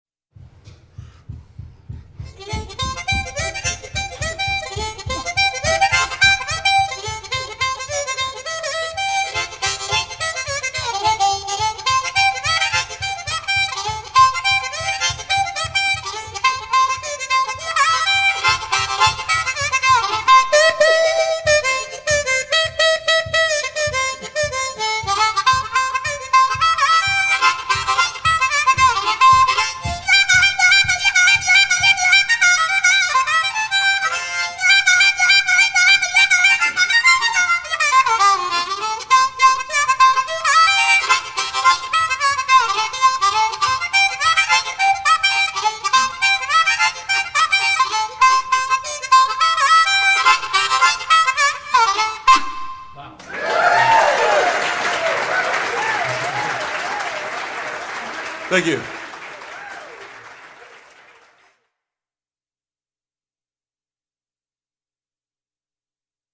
traditional song